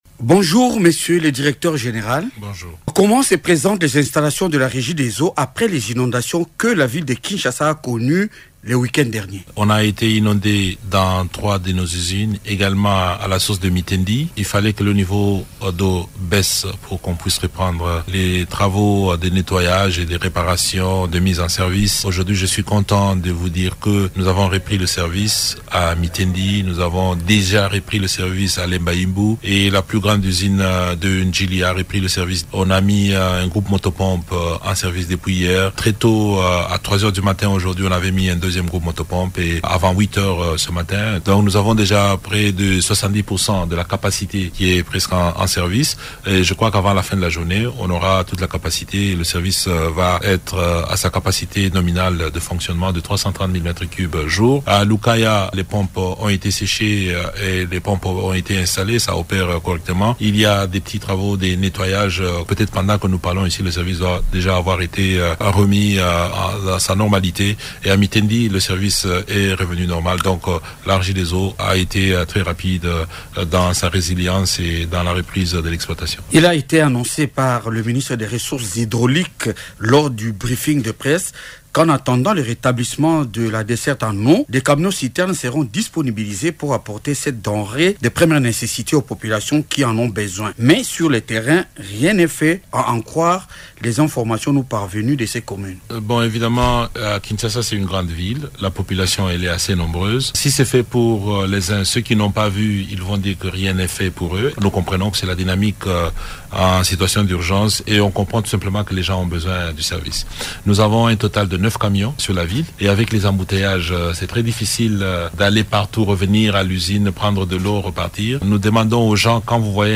lors d’une interview accordée à Radio Okapi le mercredi 9 avril.